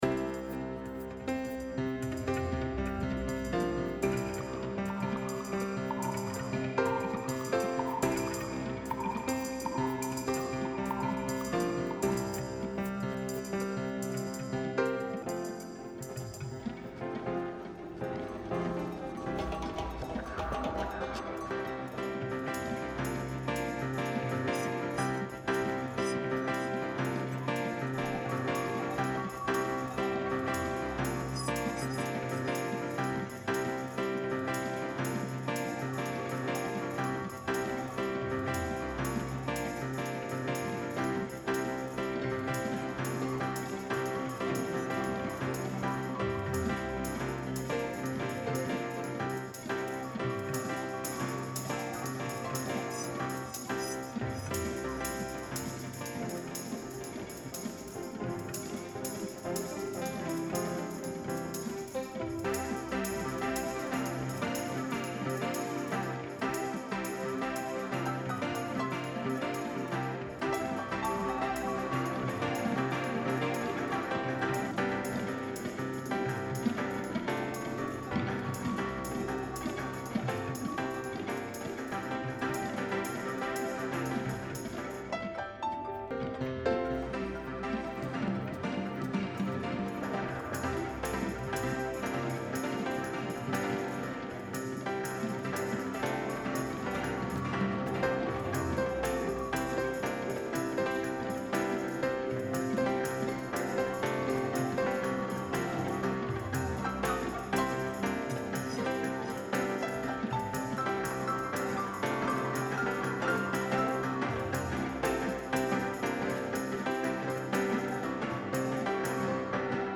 version instrumentale pianistique